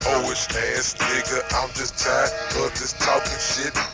Hoish Ass - Dj Sound.wav